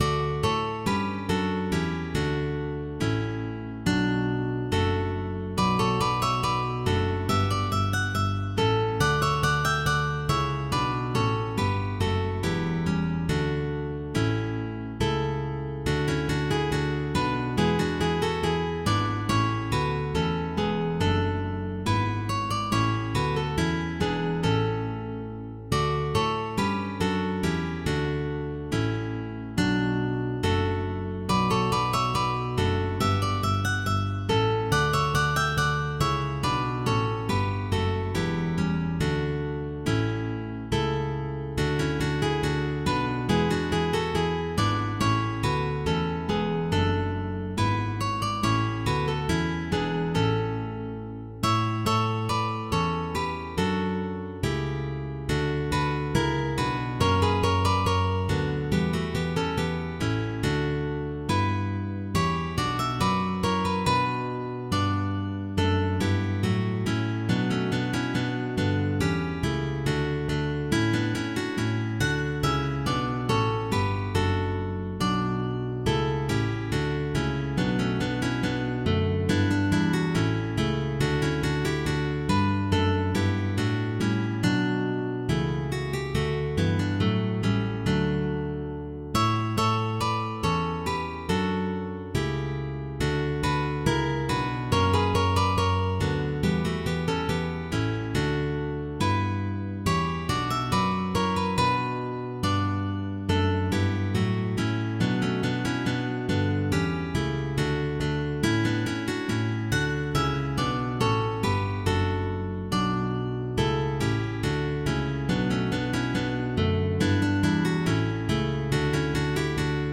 Also valid for guitar orchestra with optional bass.
Guitar trio
Baroque